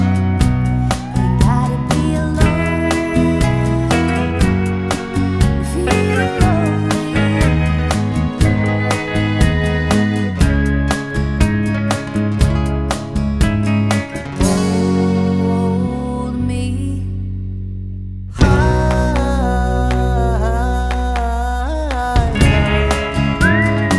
no Backing Vocals Country (Female) 3:00 Buy £1.50